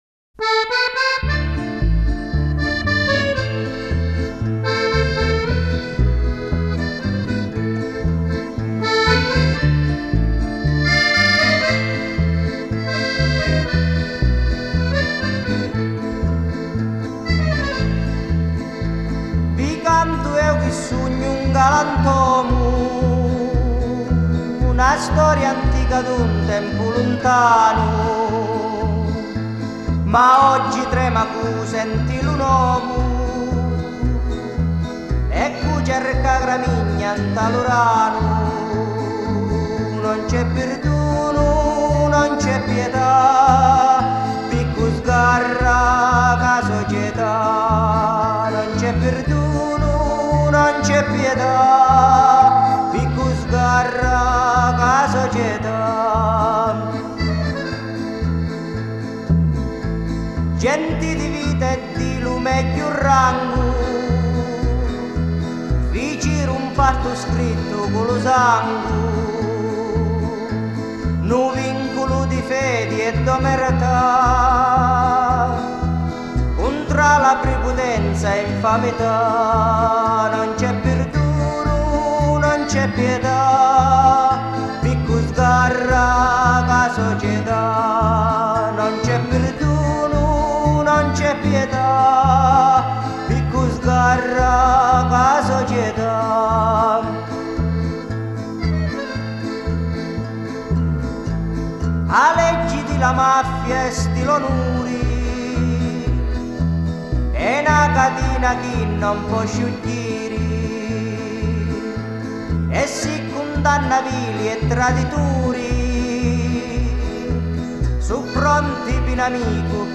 Жанр: Mafia Folk